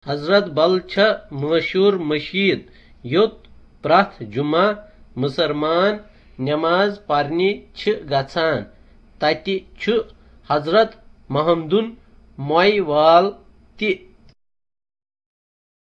A conversation on visiting the mosque at Hazratbal, ten miles from downtown Srinagar.